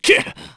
Roman-Vox_Damage_kr_01.wav